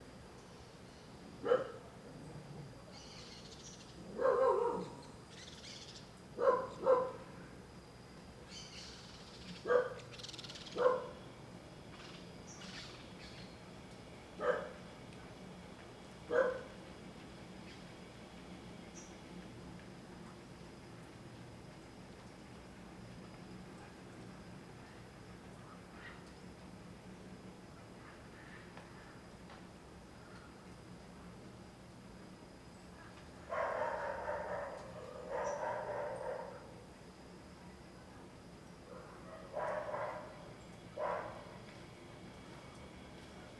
CSC-06-033-OL- Cachorro latindo e periquitos.wav